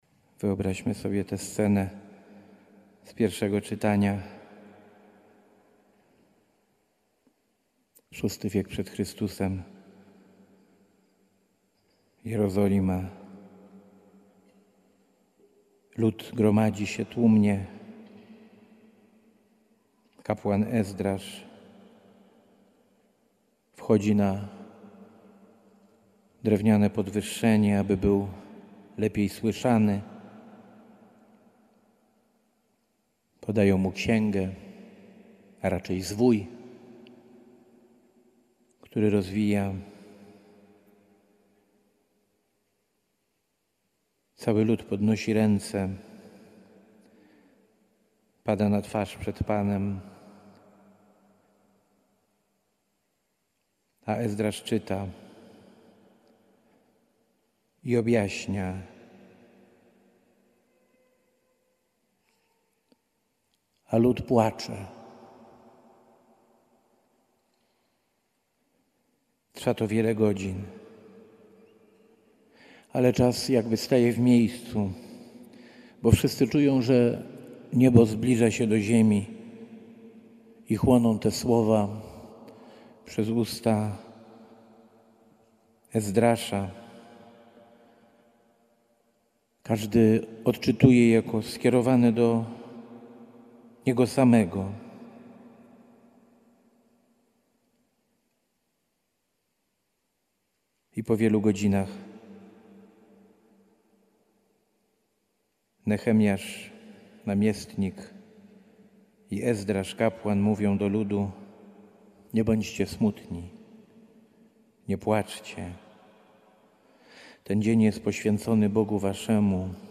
Biskup pomocniczy warszawski przewodniczył uroczystej Mszy Św. w Świątyni Opatrzności Bożej.
homilia.mp3